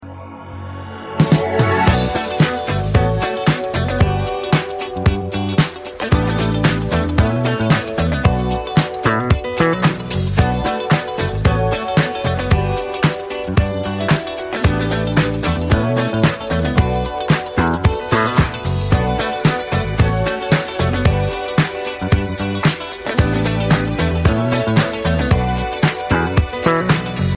Рингтоны Без Слов » # R&B Soul Рингтоны